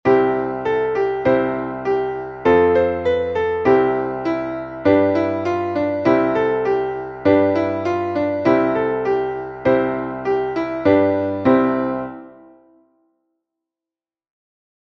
Traditionelles Kinder-/ Winterlied